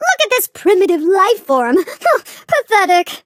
flea_kill_vo_01.ogg